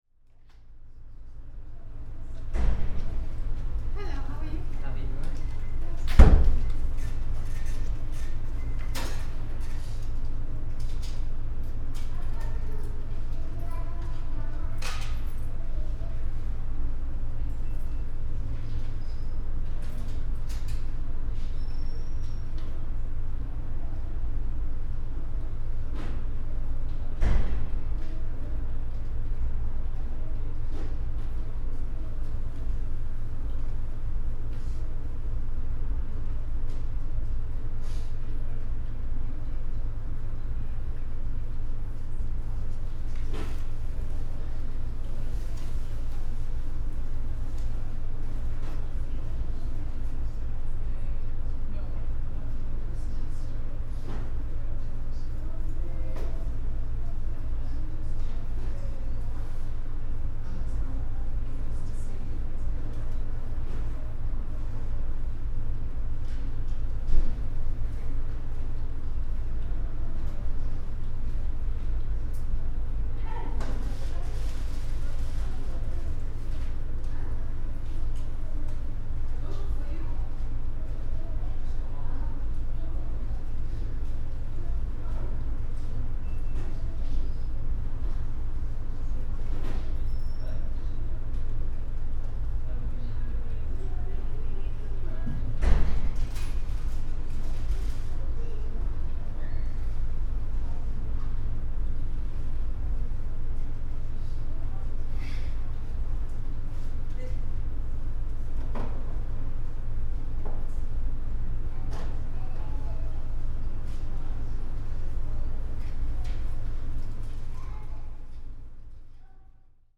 Tags: Sound Map in London London sounds UK Sounds in London London